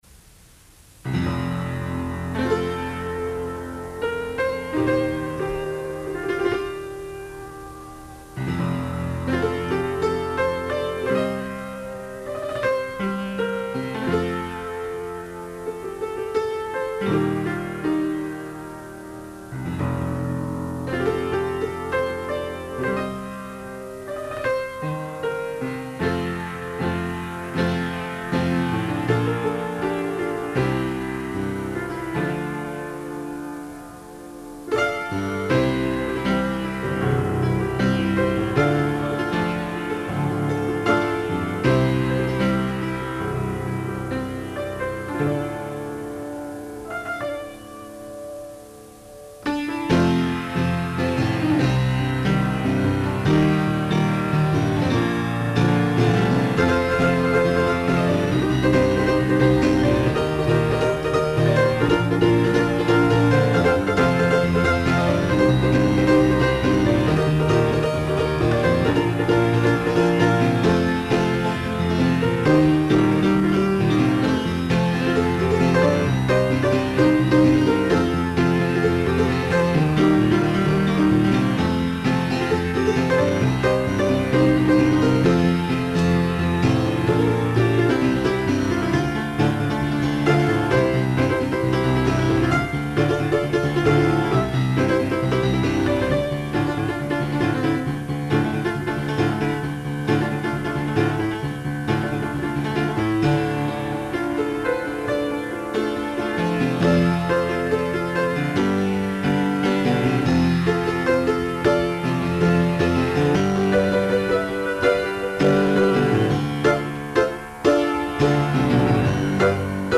Raymond Benson plays the Overture from The Resurrection of Jackie Cramer
Recorded at home during 2020 quarantine.
(The Musical)
(Demo tracks below: Composer Raymond Benson plays and sings the Cramer Overture and Prologue)